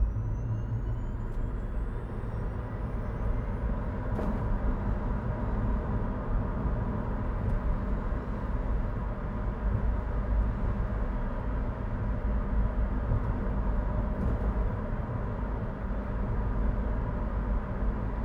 Vehicles, Electric, Tesla Model 3 2017, Driving, Medium, Engine Perspective SND136636.wav